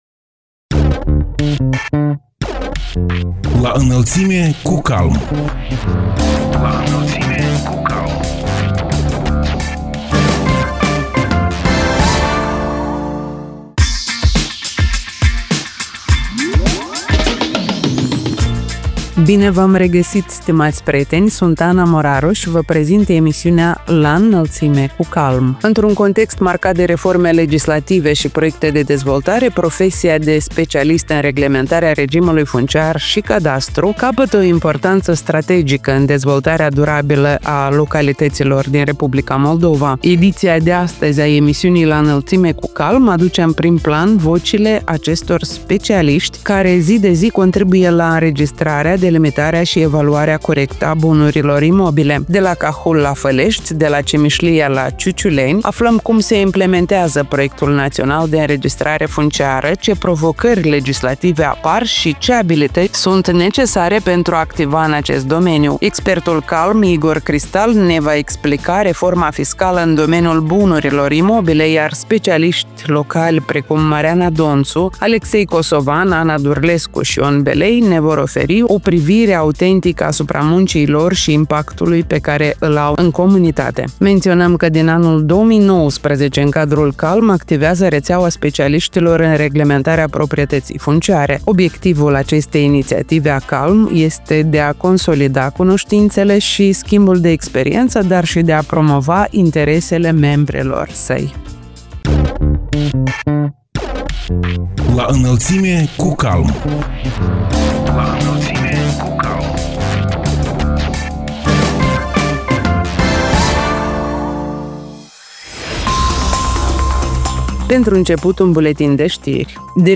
Emisiunea „La Înălțime cu CALM” aduce în prim-plan vocile acestor specialiști care, zi de zi, contribuie la înregistrarea, delimitarea și evaluarea corectă a bunurilor imobile.